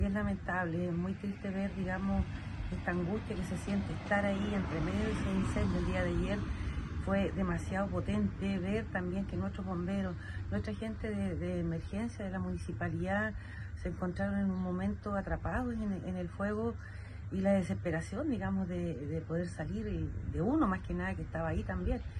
La alcaldesa Jacqueline Romero comentó además de la angustia que ha significado para la comuna este siniestro que si bien no ha dañado viviendas ni personas, tuvo un significativo trabajo del personal a cargo.